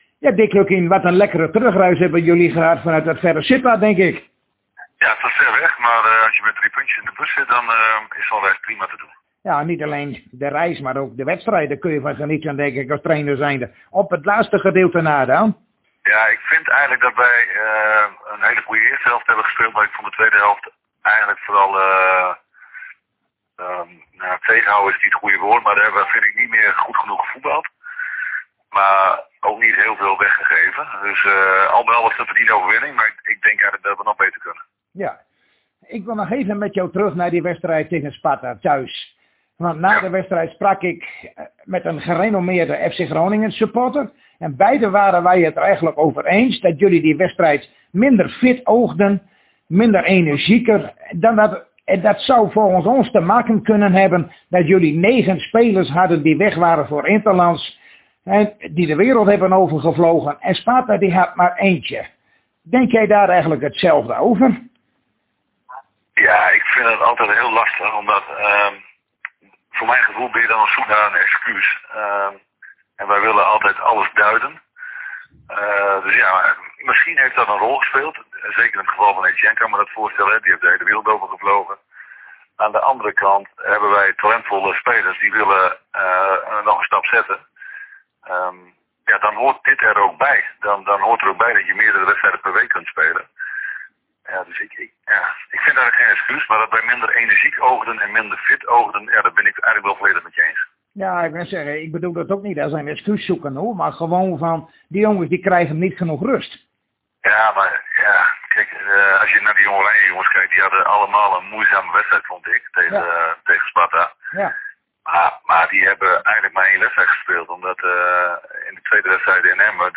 Zojuist spraken wij weer met trainer Dick Lukkien over de wedstrijd in het KNVB bekertoernooi die FC Groningen morgenavond gaat spelen tegen Sparta Rotterdam in Rotterdam.